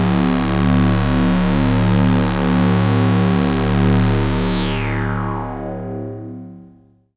LUCKY LOW D.wav